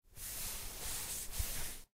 ruffle.ogg